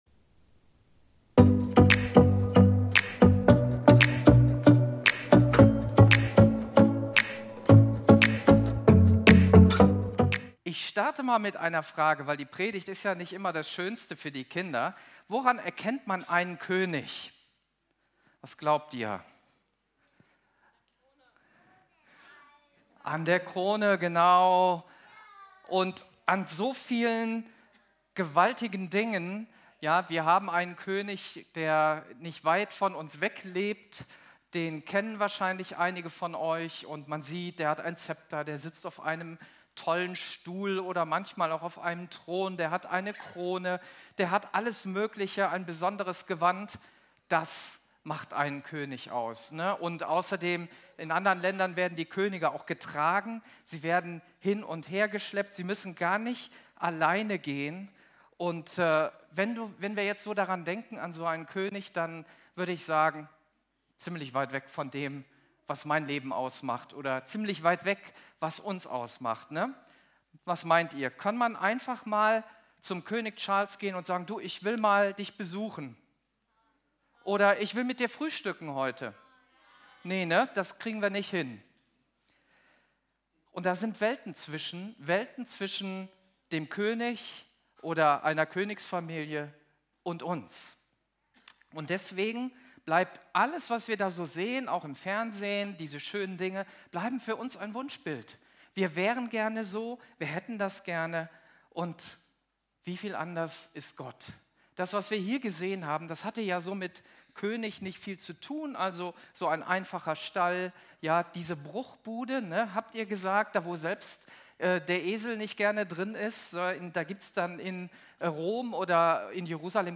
Video und MP3 Predigten